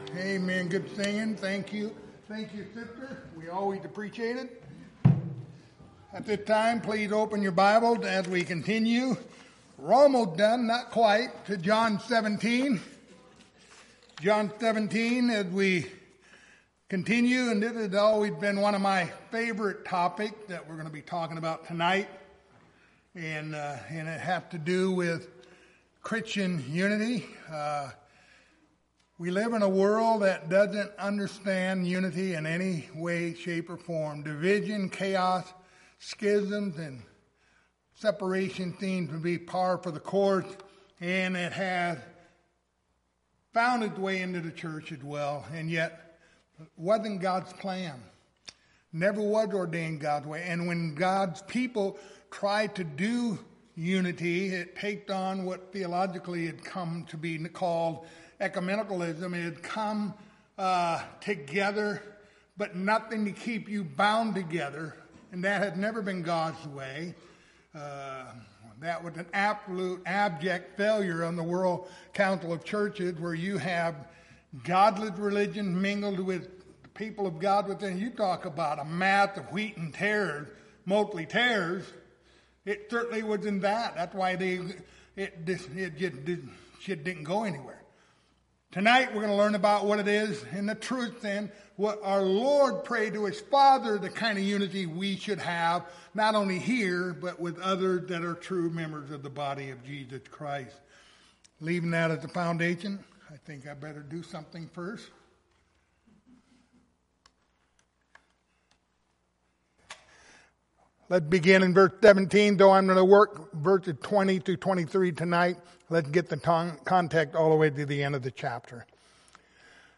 Passage: John 17:20-24 Service Type: Wednesday Evening